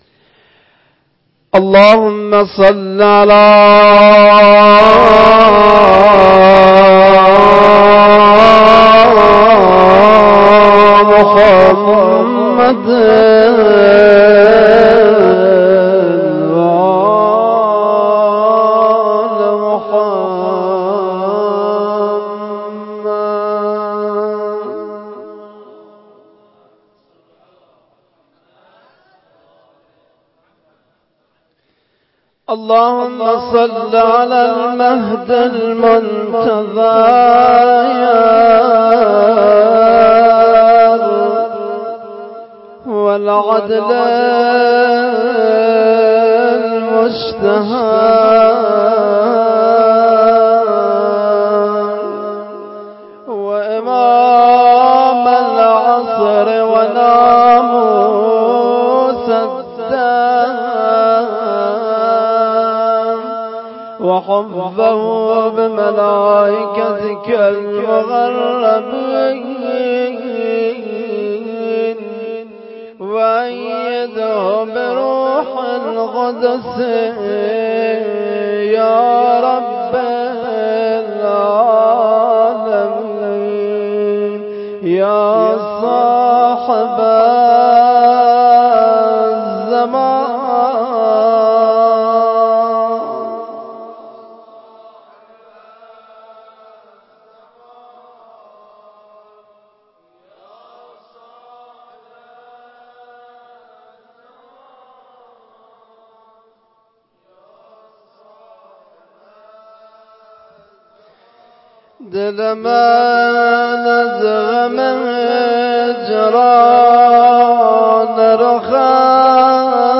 روضه
جلسه هفتگی 26-9-93.mp3